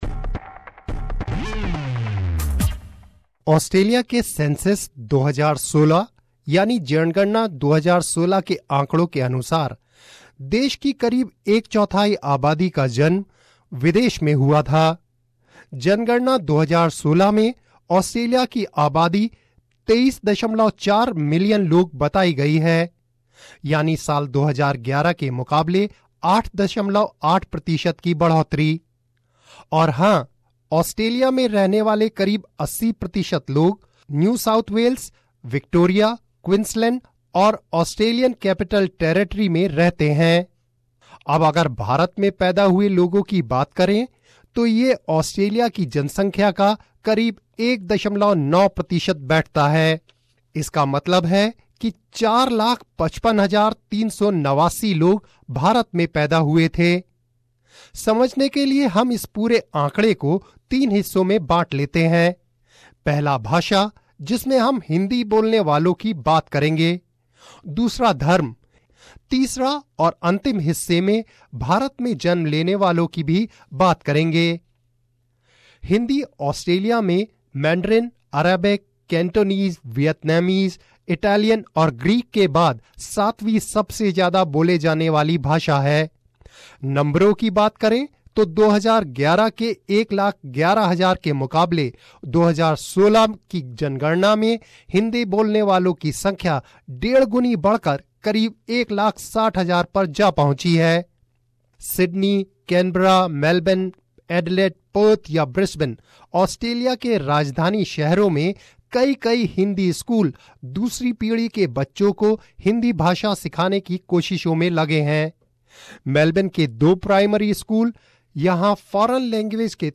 detailed report